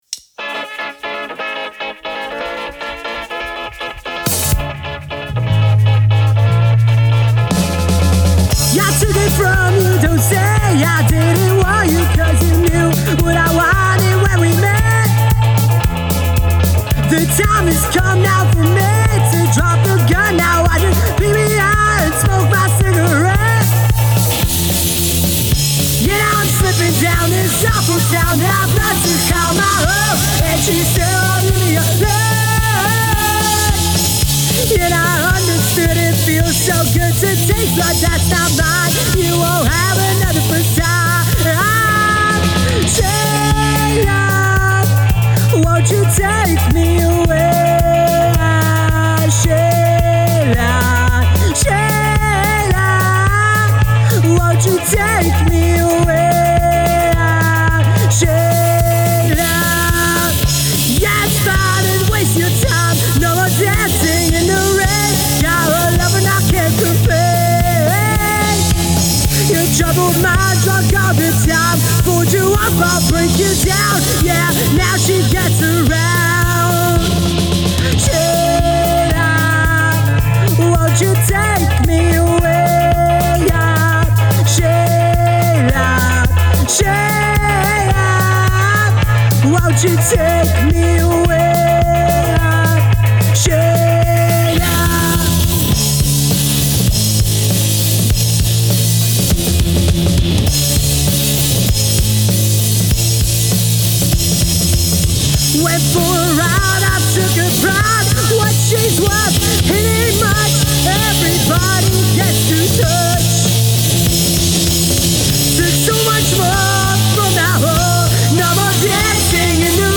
For more Punk/Ska Dance Music Check us out on Facebook!